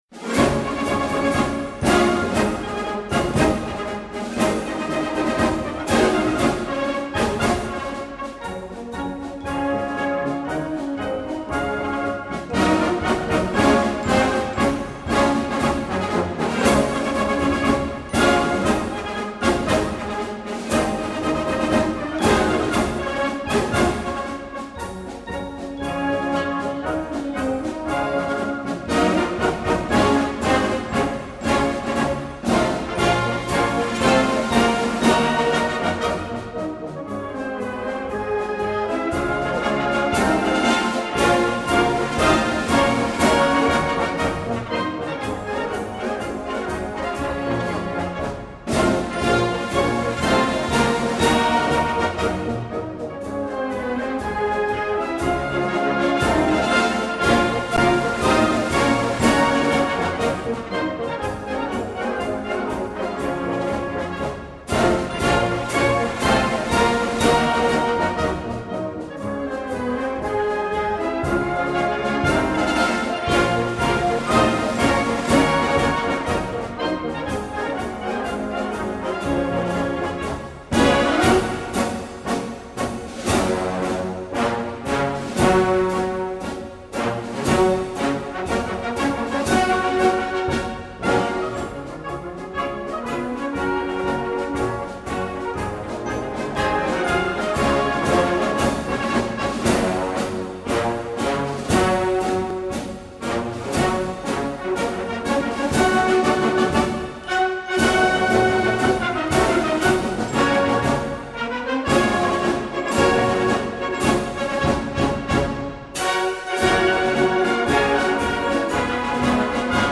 Gattung: Marsch Besetzung: Blasorchester Tonprobe